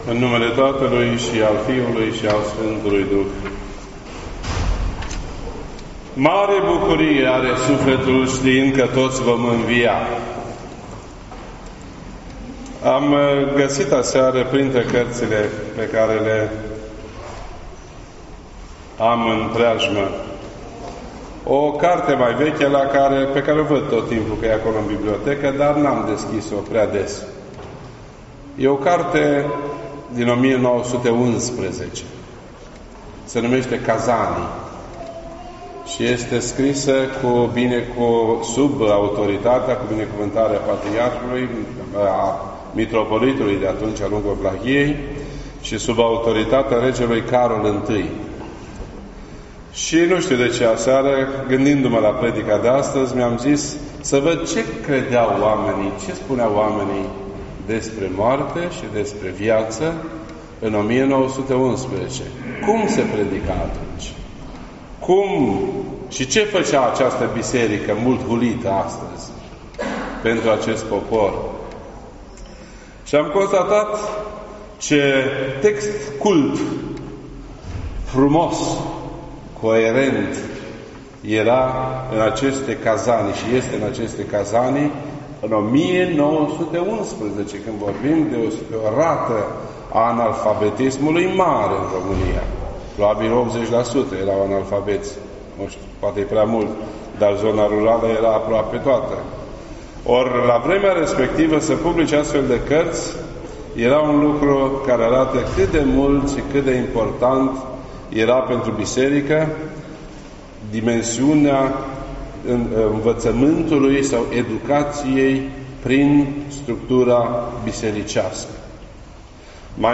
This entry was posted on Sunday, October 6th, 2019 at 4:21 PM and is filed under Predici ortodoxe in format audio.